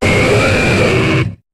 Cri d' Onix dans Pokémon HOME .